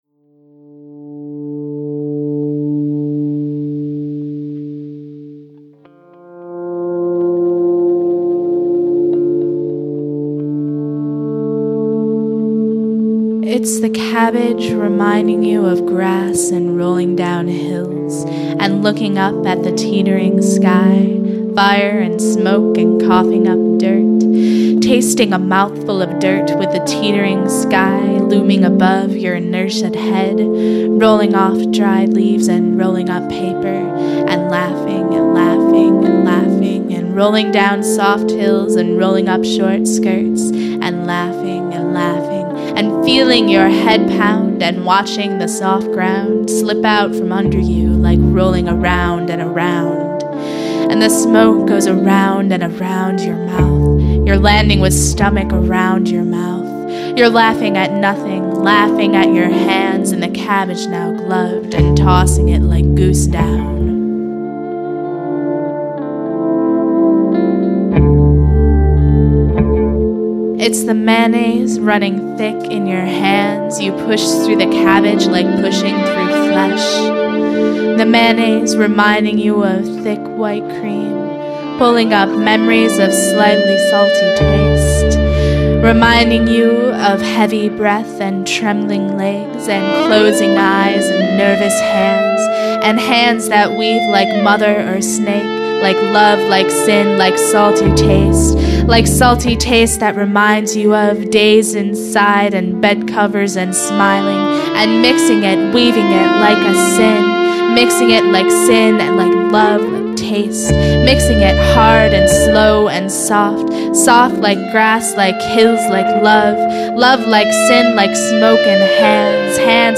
Spoken Word 1.mp3